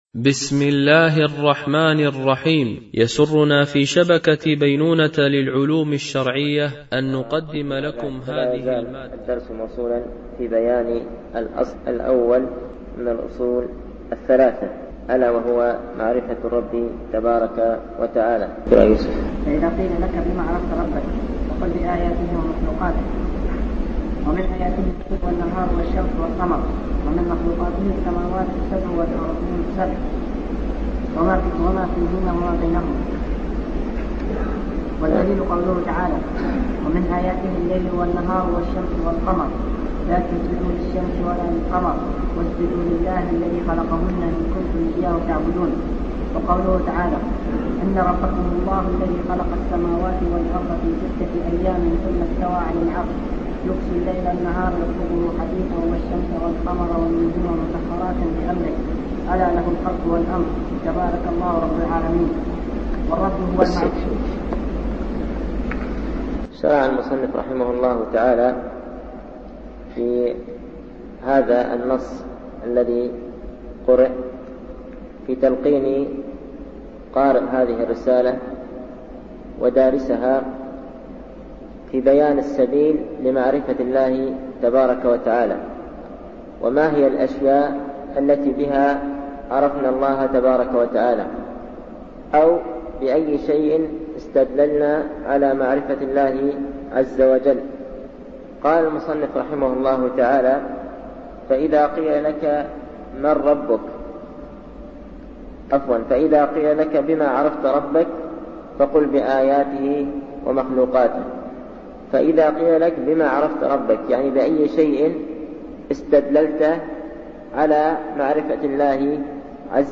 الأدلة على معرفة الرب تعالى ـ الدرس الخامس